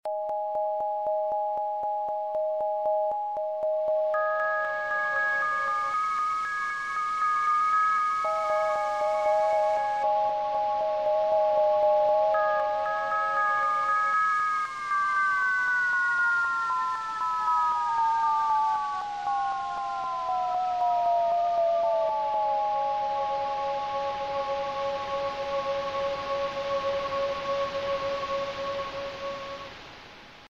16 Bit Digital Synthesizer
demo bell: 1 -